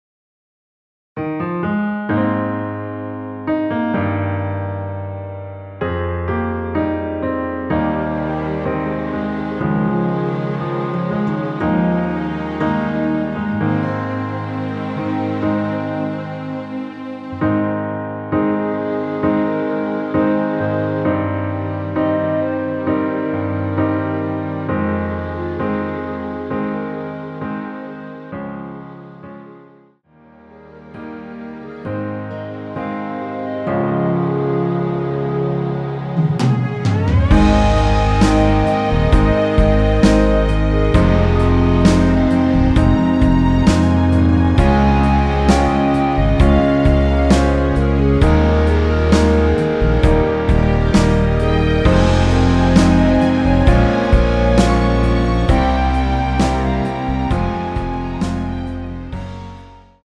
원키에서(-1)내린 멜로디 포함된 MR입니다.
Ab
노래방에서 노래를 부르실때 노래 부분에 가이드 멜로디가 따라 나와서
앞부분30초, 뒷부분30초씩 편집해서 올려 드리고 있습니다.
중간에 음이 끈어지고 다시 나오는 이유는